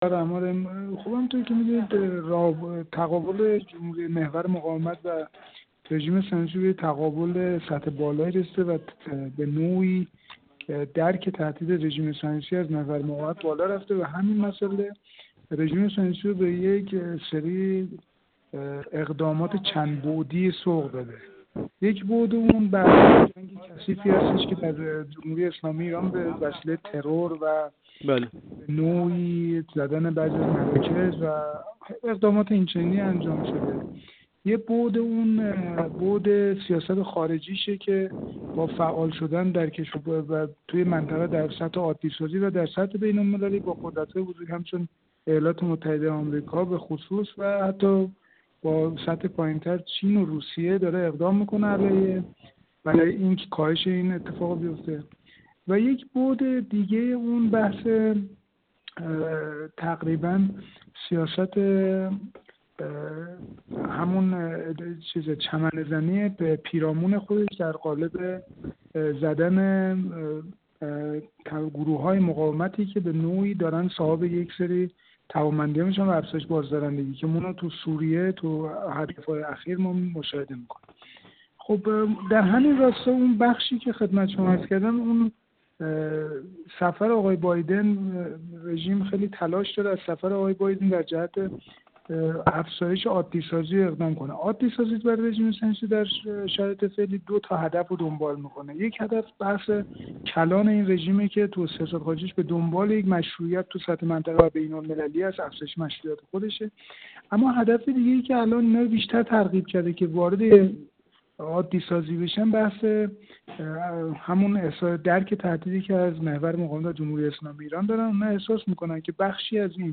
کارشناس مسائل غرب آسیا
گفت‌وگو